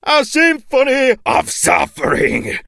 chuck_die_vo_01.ogg